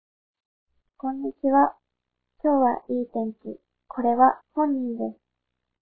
もう1つ、女性のリファレンス音声
woman.wav